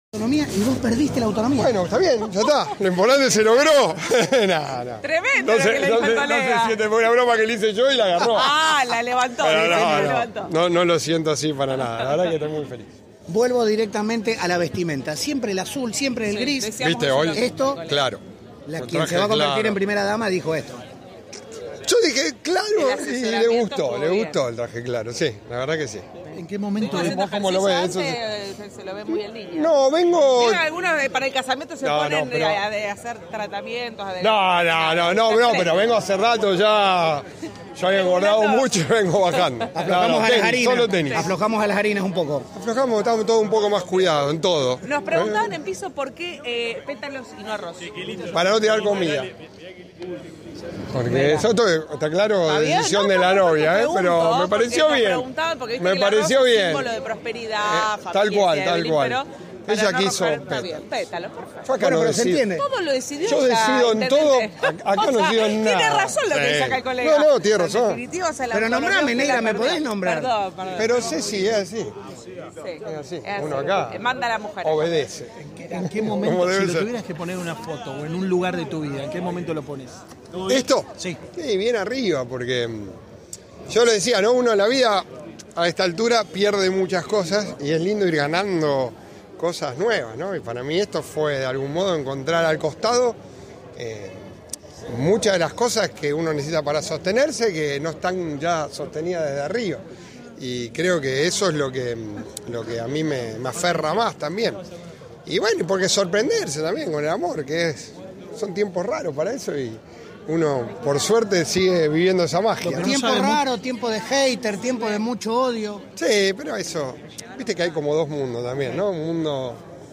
Tras dar el “sí”, Javkin expresó su felicidad y habló con Cadena 3 Rosario con humor sobre los preparativos previos.